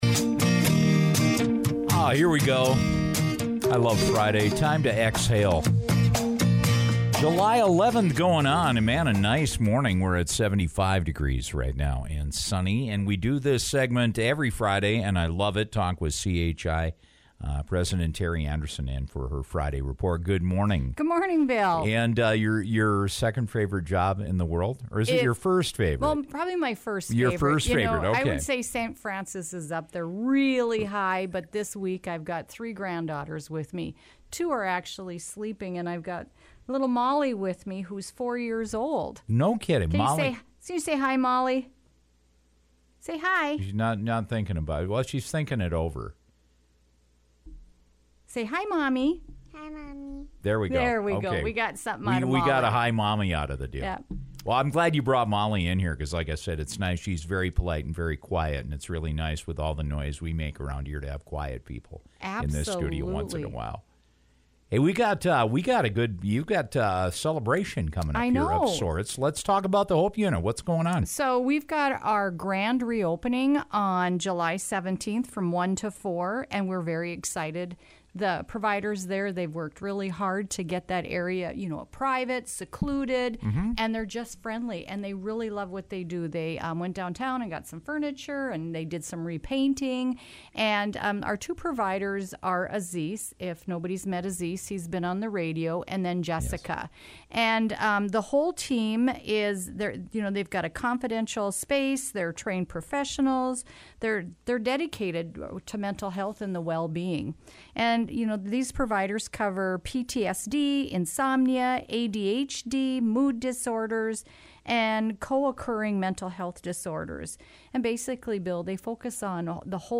dropped by the Morning Show today to talk about The Hope Unit.